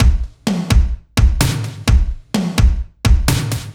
Index of /musicradar/french-house-chillout-samples/128bpm/Beats
FHC_BeatB_128-02_KikSnrTom.wav